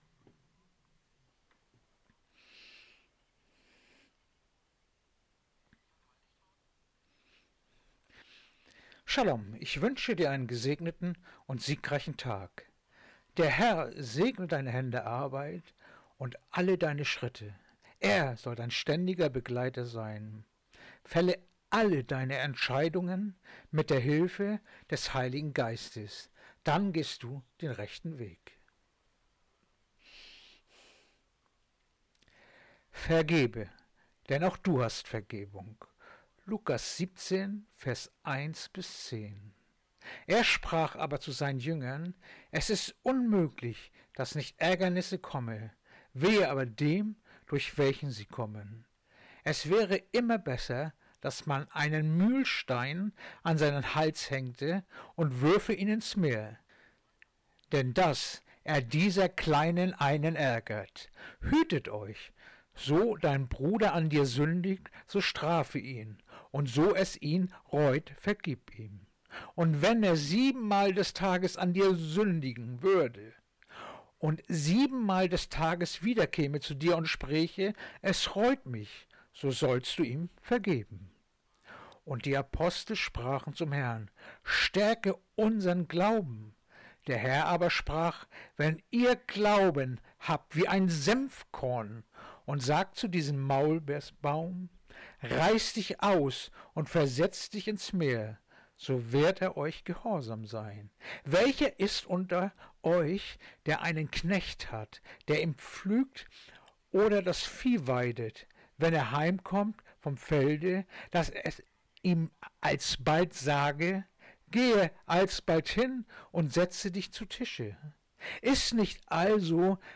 Andacht-vom-12.-November-Lukas-17-1-10